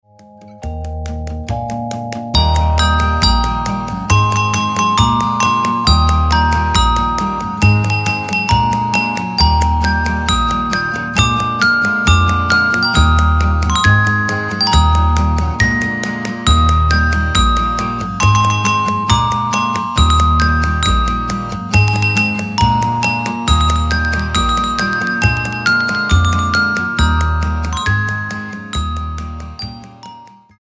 --> MP3 Demo abspielen...
Tonart:Eb ohne Chor